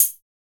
HOUSE CHH 2.wav